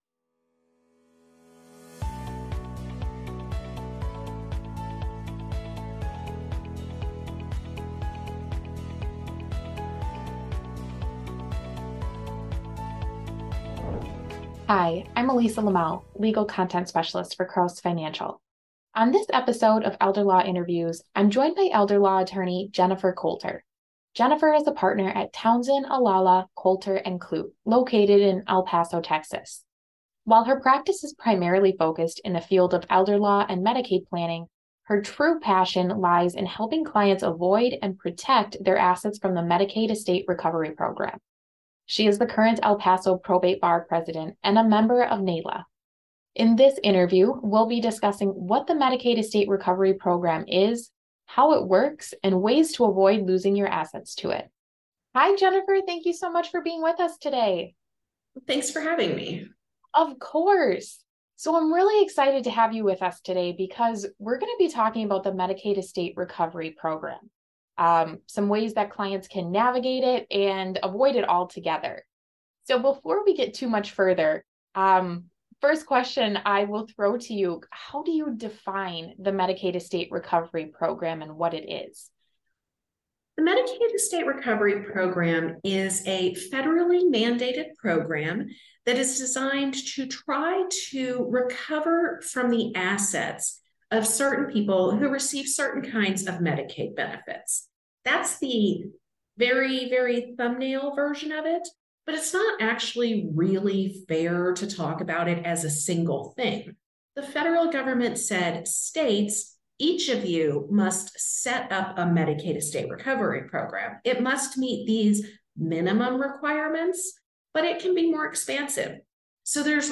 Hear what the Medicaid Estate Recovery program is and how to protect your client's assets in this elder law interview